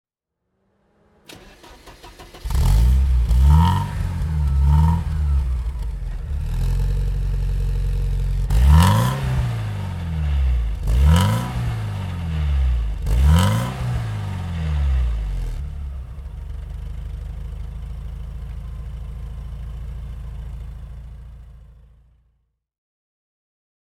Porsche 924 S (1985) - Starten und Leerlauf